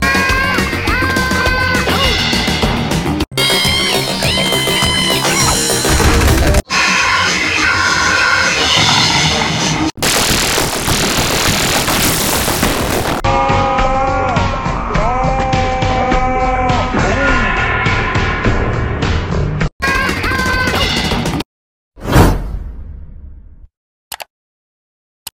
5 Pocoyo & Nina "Scream & Running" Sound Variations in 25 sec.